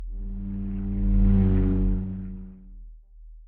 Sci-Fi Sounds / Movement / Fly By 05_3.wav
Fly By 05_3.wav